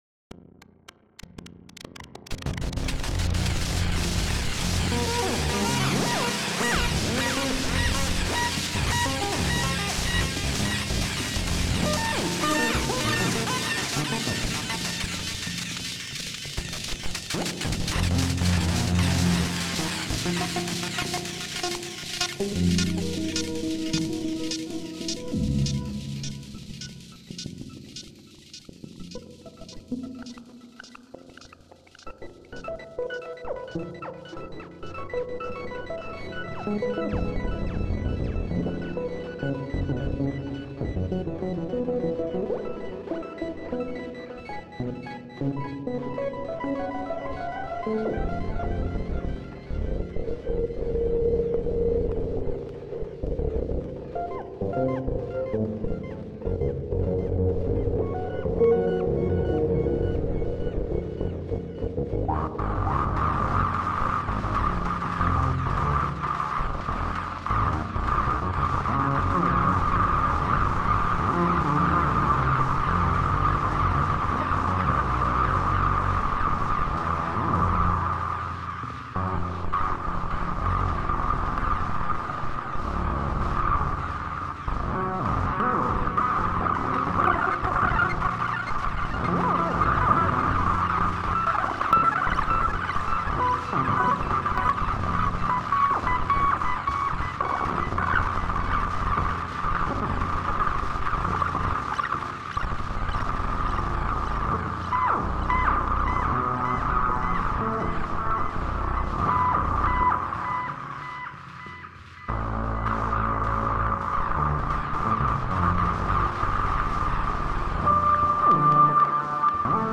Atmósfera sintética futurista
Grabación de sonido sintético creado para simular una atmósfera futurista
envolvente
electrónica
sintetizador